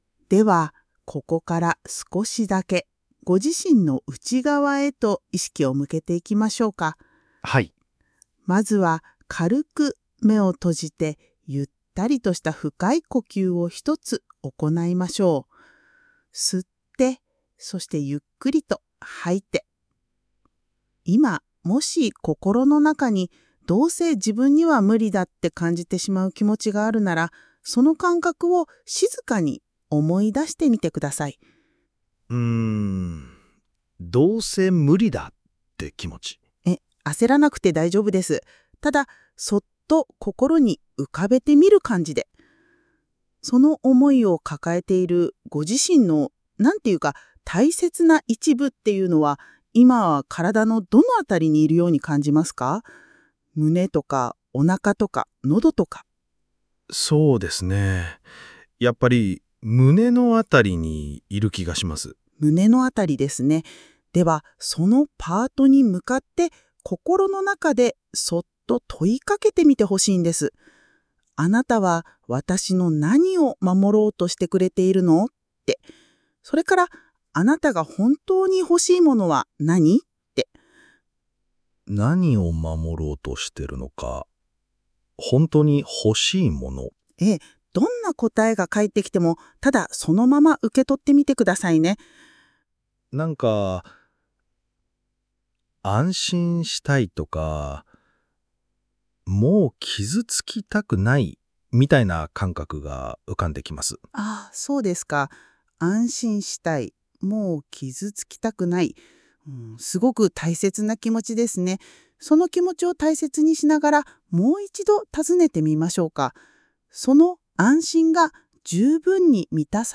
１分間のコアトランスフォーメーション誘導
1-minute_ct_meditation.mp3